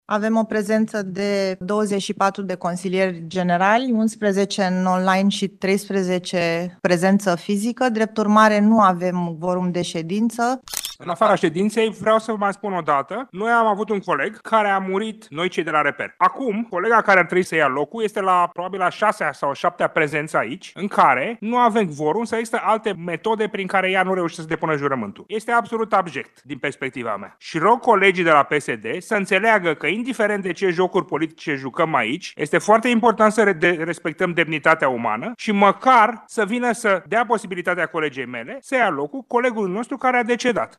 Consilier din partea REPER: „Este absolut abject din perspectiva mea”